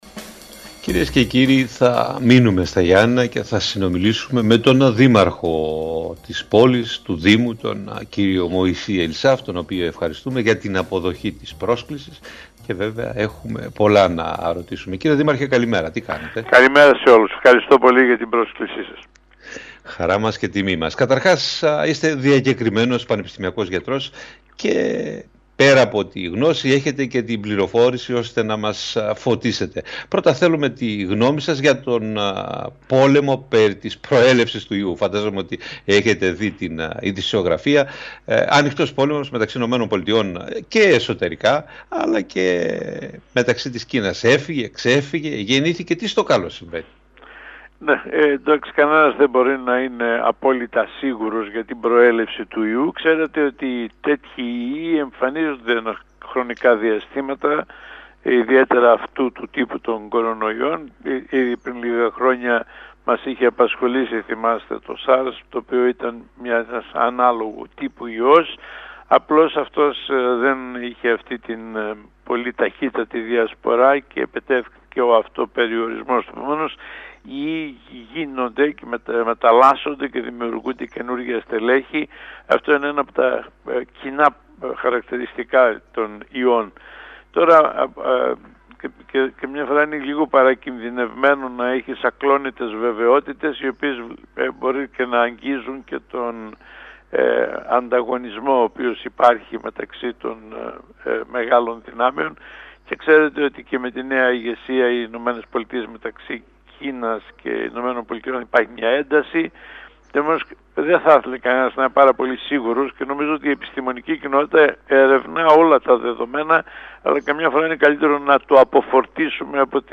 Για την ανάγκη διατήρησης και αξιοποίησης και πέραν του υγειονομικού πεδίου της κεκτημένης γνώσης, της εμπειρίας και των υποδομών που πρόκυψαν από την επιτυχή διαχείριση της πανδημίας μίλησε στην ΕΡΤ Ηπείρου ο Μωυσής Ελισάφ.